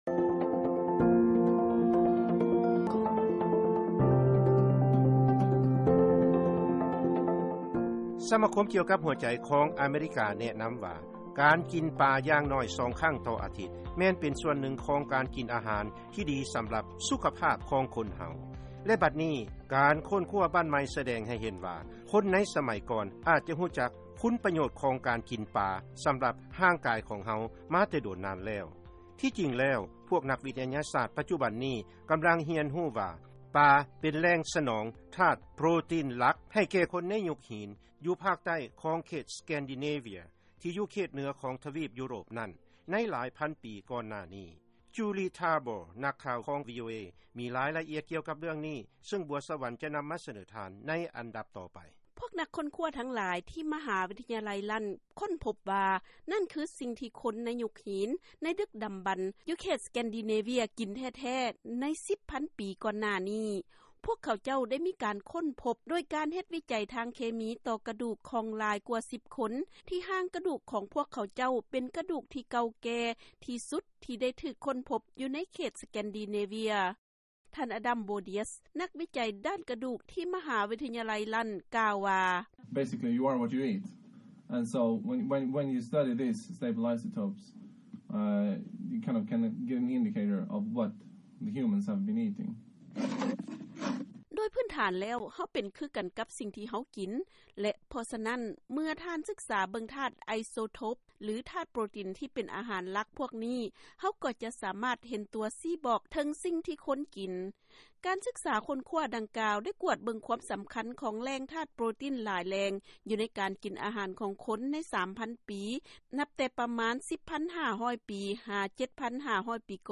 ເຊີນຟັງລາຍງານເລື້ອງການກິນອາຫານຂອງຄົນໃນເຂດສະແກນດີເນເວຍ ທີ່ກິນປາເປັນຫລັກ